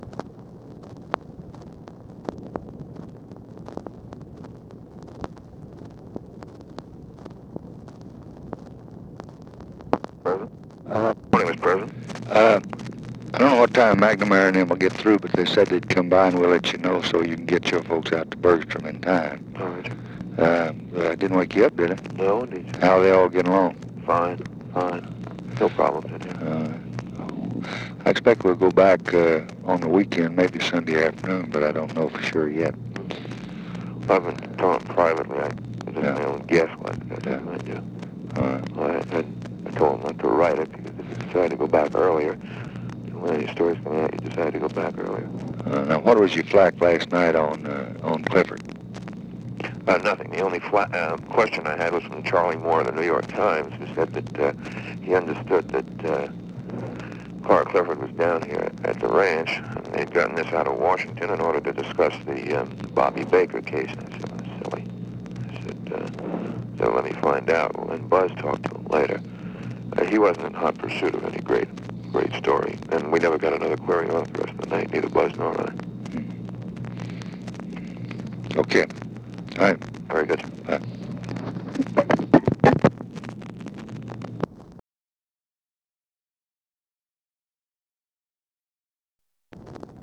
Conversation with MAC KILDUFF, November 10, 1964
Secret White House Tapes